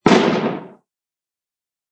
Descarga de Sonidos mp3 Gratis: explosion 23.